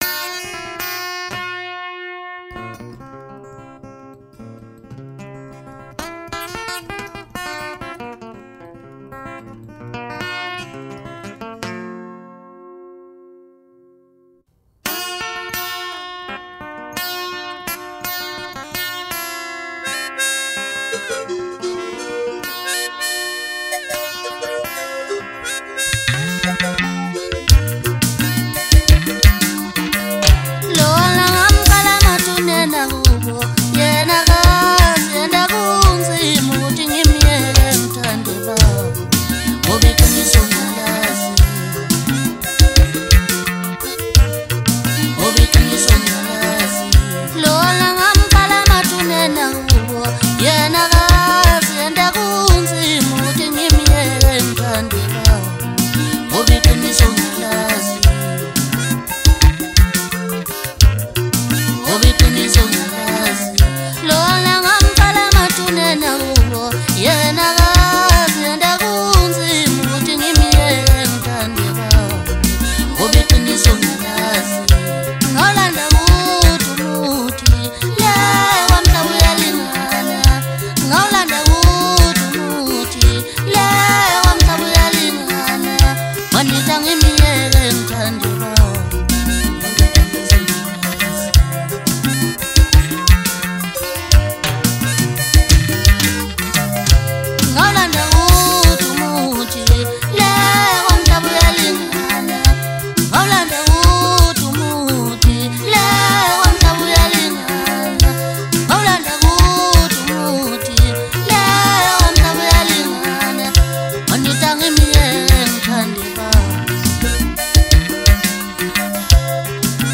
MASKANDI MUSIC
maskandi song